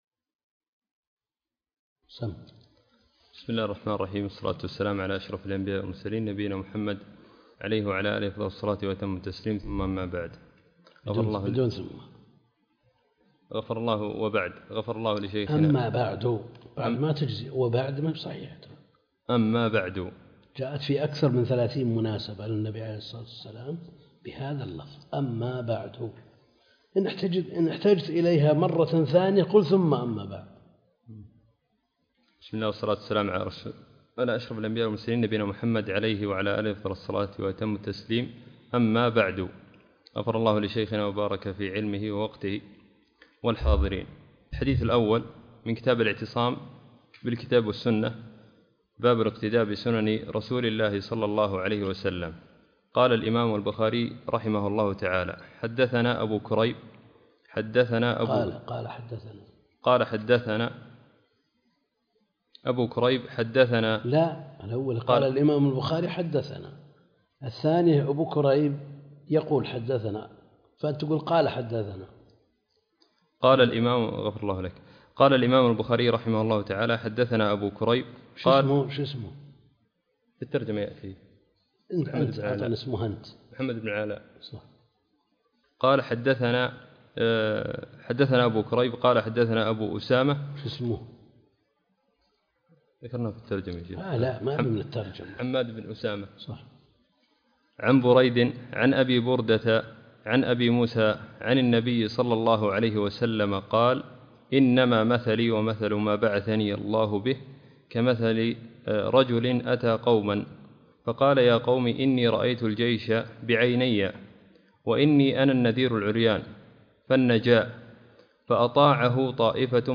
تفاصيل المادة عنوان المادة الدرس (9) التحليلي تاريخ التحميل الأحد 12 مارس 2023 مـ حجم المادة 23.69 ميجا بايت عدد الزيارات 250 زيارة عدد مرات الحفظ 137 مرة إستماع المادة حفظ المادة اضف تعليقك أرسل لصديق